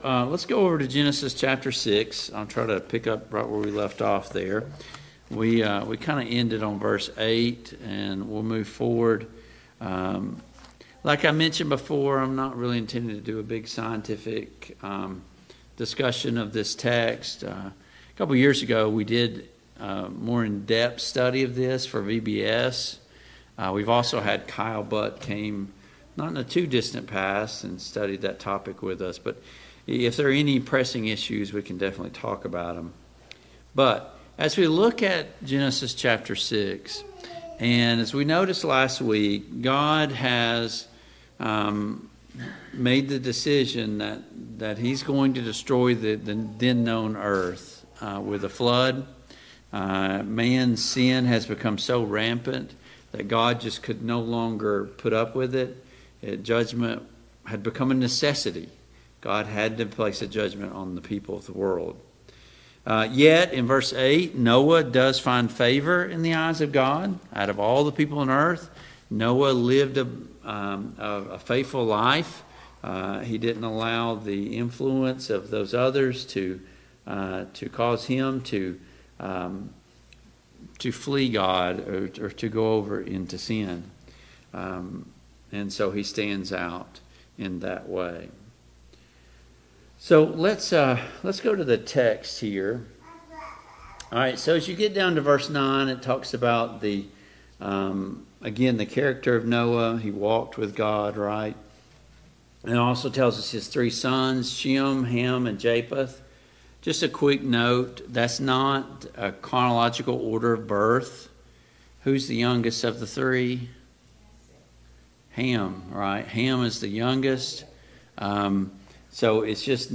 Study of Genesis Passage: Genesis 6:8-22 Service Type: Family Bible Hour Topics